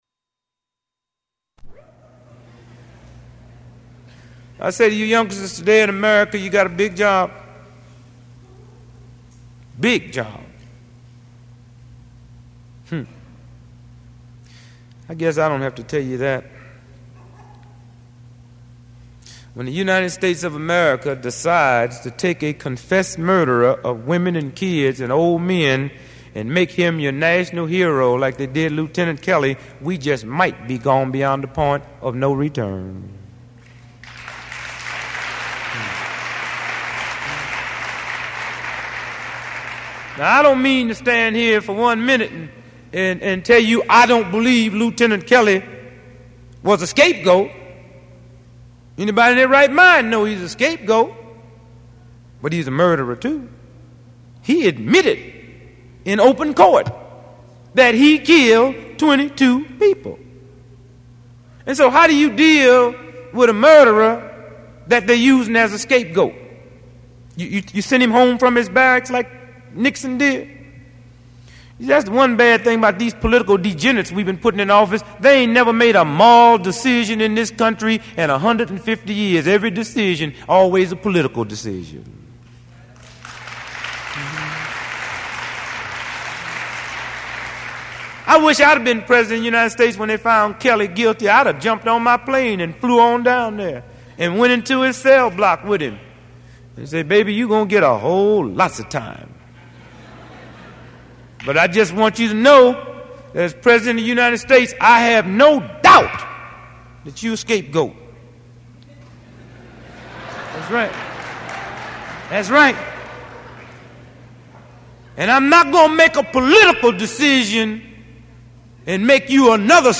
This is a speech that the political comedian Dick Gregory gave at San Francisco State in 1971.